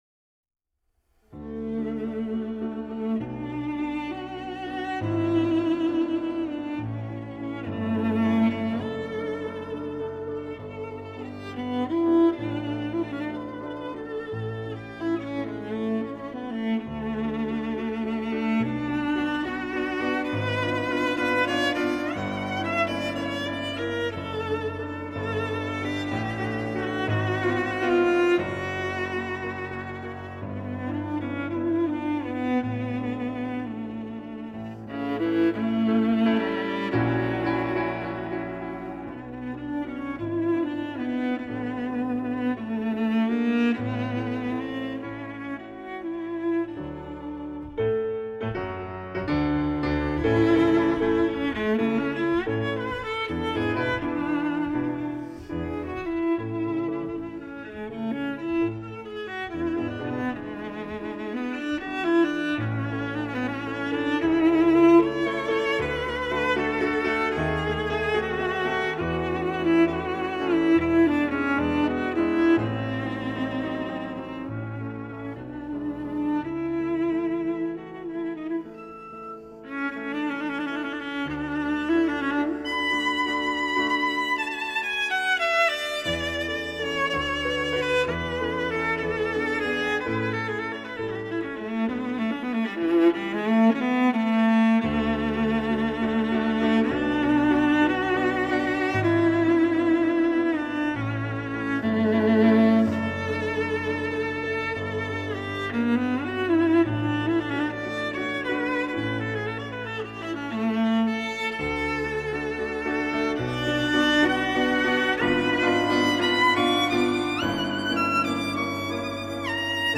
Viola
piano